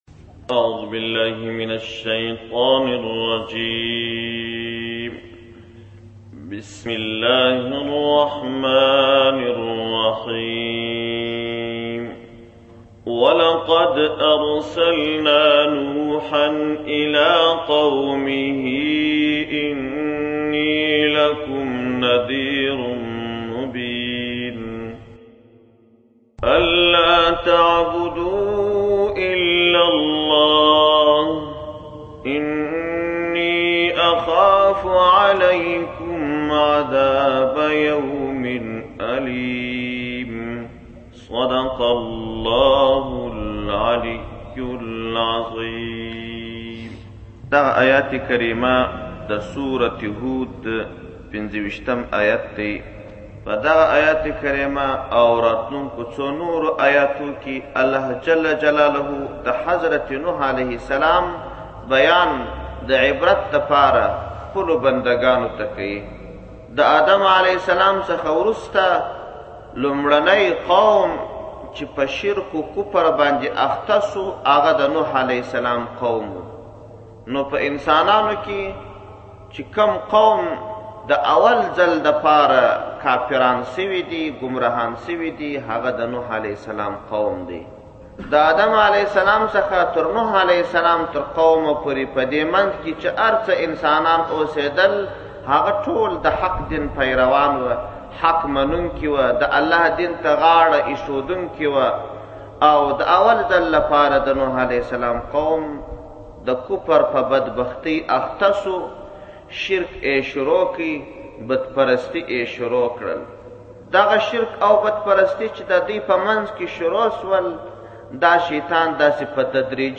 مې 7, 2017 تفسیرشریف, ږغیز تفسیر شریف 1,029 لیدنی